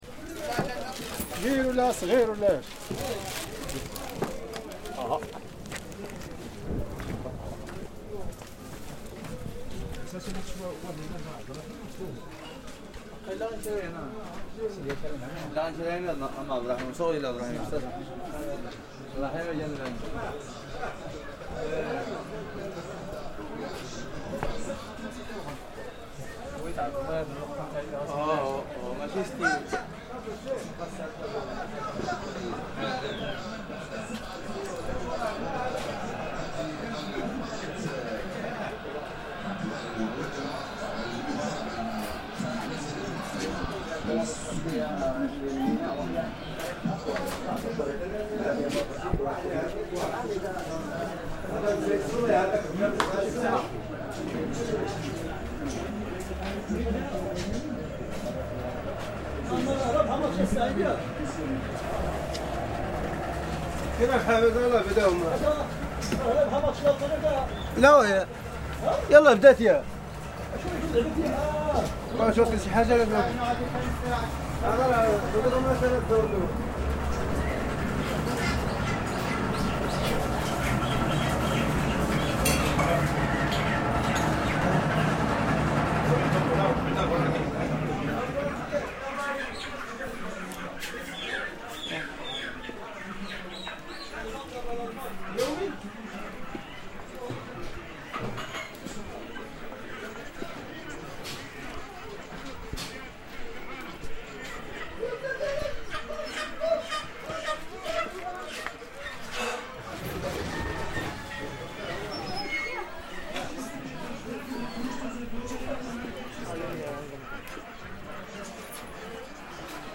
Chicken slaughter in a Moroccan souk
A walk around the poultry souk in Essaouira, Morocco - listen closely at the end and you can hear the somewhat unpleasant sound of a chicken being slaughtered.